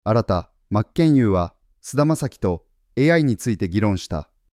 人名や専門用語は漢字ではなくひらがなで入力したりすることで、より正確な発音になります。